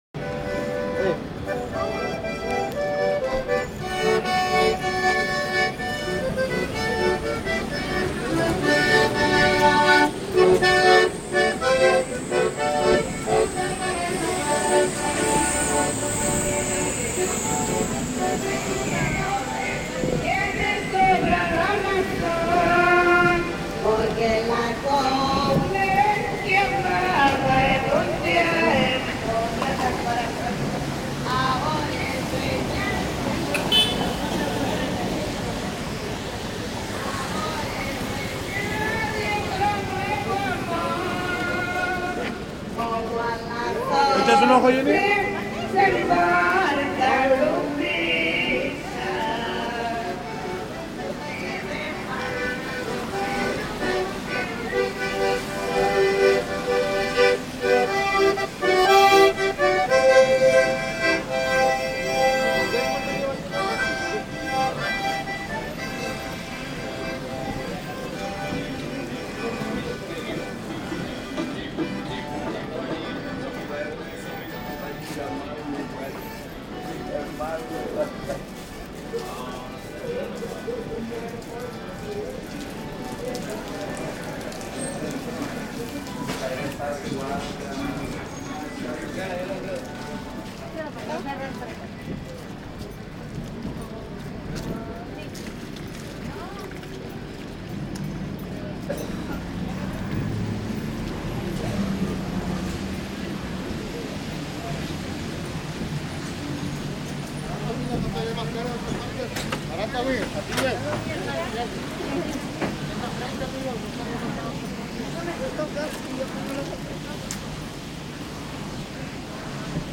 The sound of Tijuana in the rain
Zona-Centro-Tijuana-rainy-walk.mp3